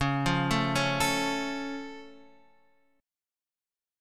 C#+ chord